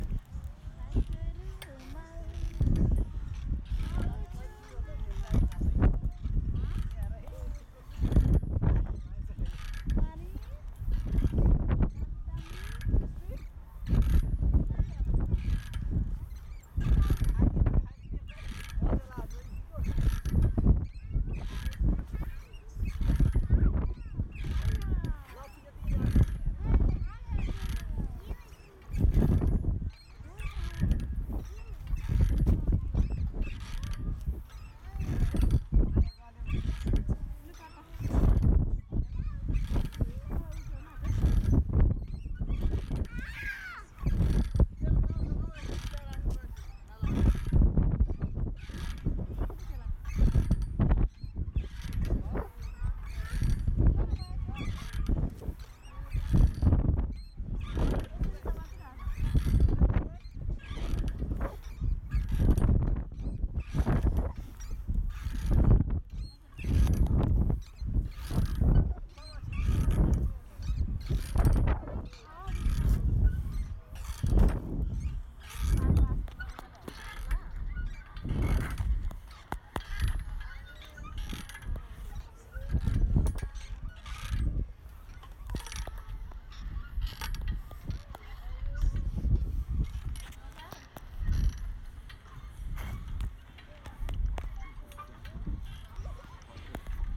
mystery soundscape, let's see if they can figure out what the hell is going on here? :)
It was very difficult to hold on with one hand and record with the other, and I was probably one of the older people in this context LOL